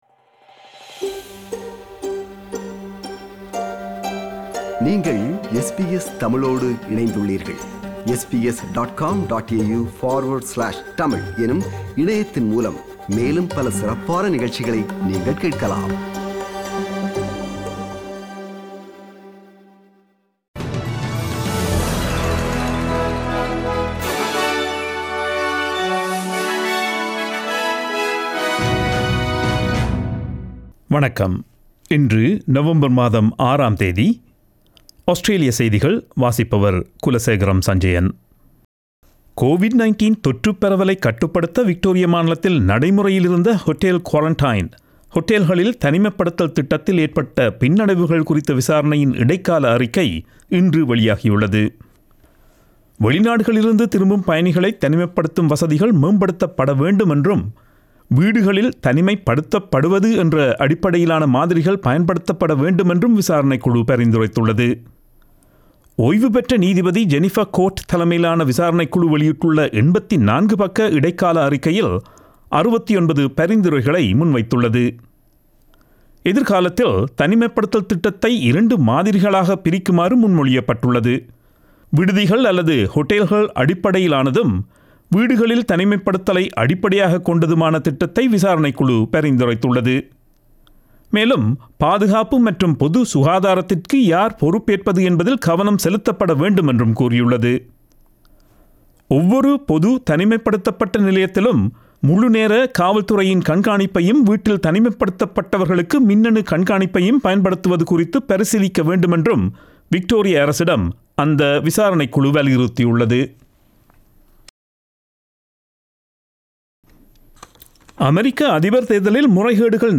Australian news bulletin for Friday 06 November 2020.